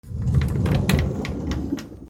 / K｜フォーリー(開閉) / K05 ｜ドア(扉)
引き戸 普通に